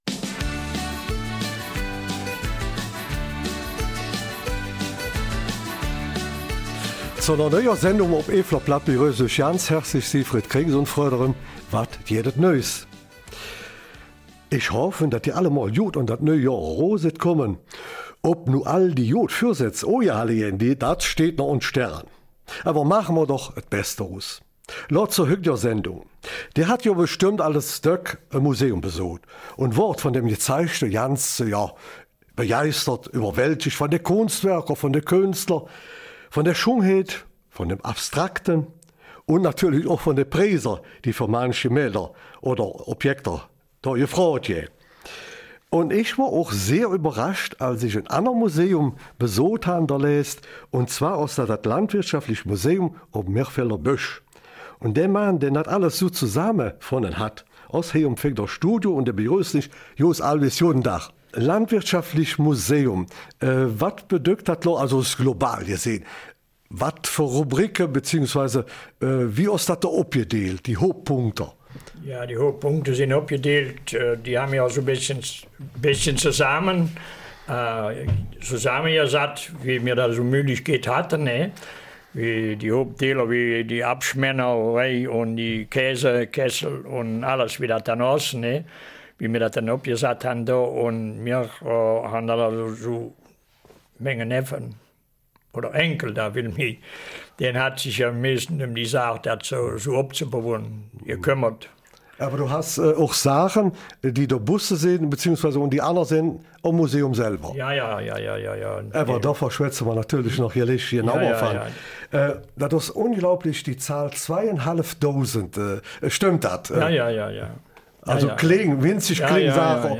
Eifeler Mundart: Landwirtschaftliches Museum Mirfelder Busch 31.